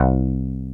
WOOD BASS 2.wav